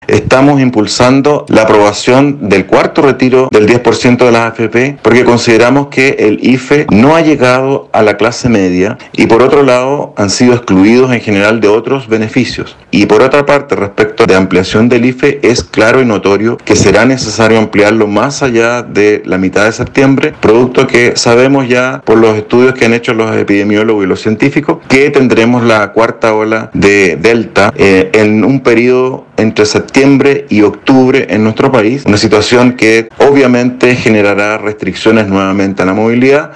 El congresista del Frente Amplio, Patricio Rosas, indicó que junto a la diputada Pamela Jiles impulsó el segundo y tercer retiro de fondos de las AFP, y que también lo hará con este cuarto proyecto.